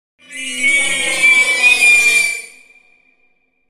Alien Screaming ringtone free download
Sound Effects